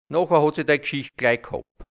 Mundart-Wörter | Mundart-Lexikon | deutsch-hianzisch | Redewendungen | Dialekt | Burgenland | Mundart-Suche: E Seite: 10
Redewendungen